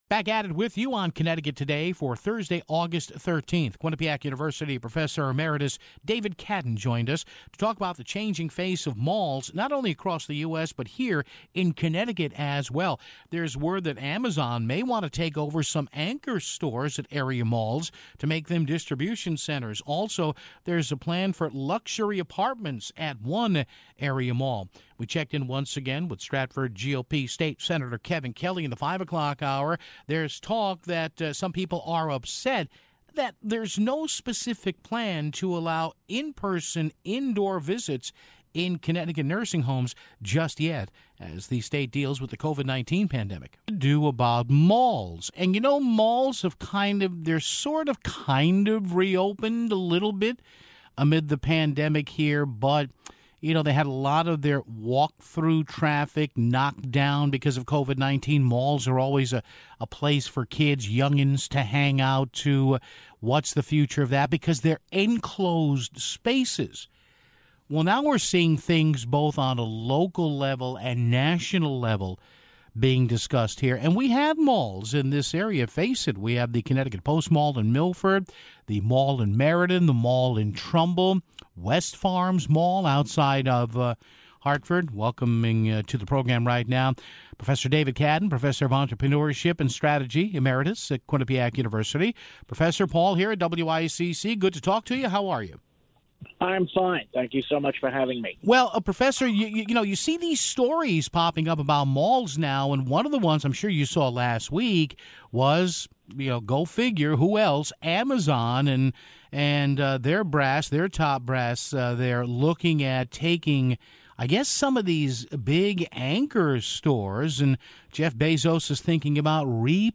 Then towards the end of the show, State Senator Kevin Kelly called in to talk about the latest with nursing homes in the state.